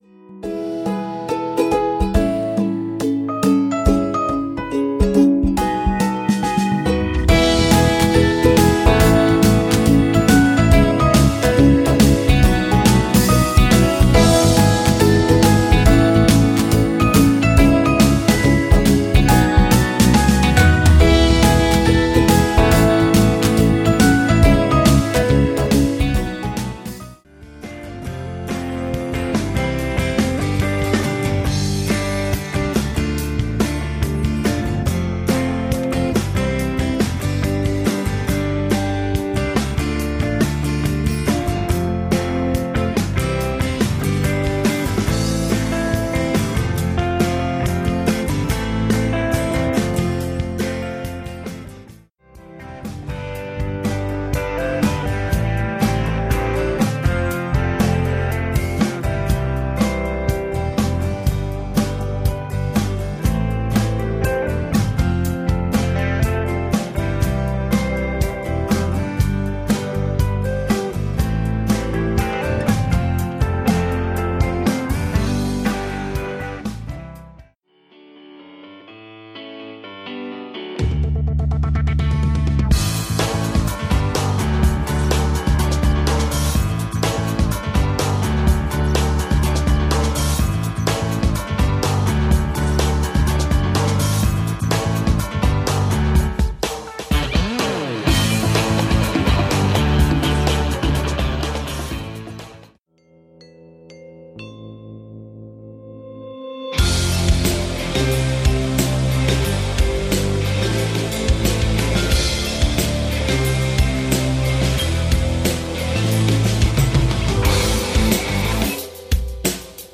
styl - world/pop/rock